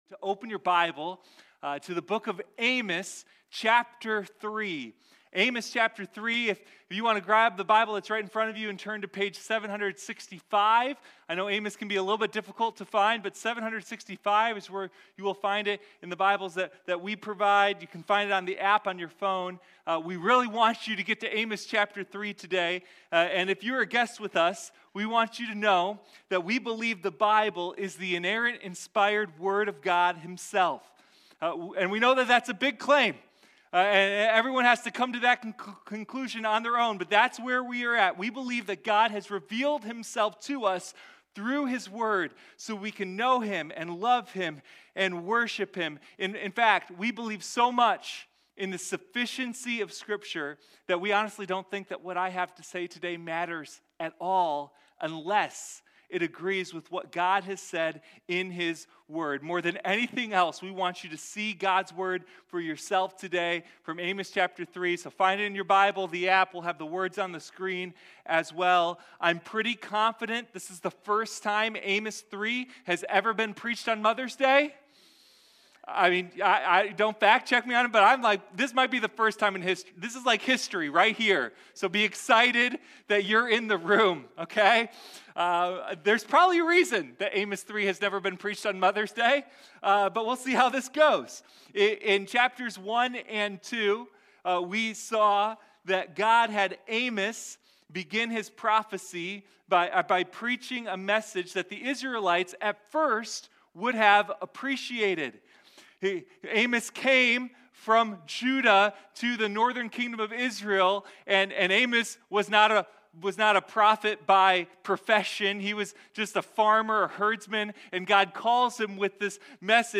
Sunday Morning Amos: The Roar of Justice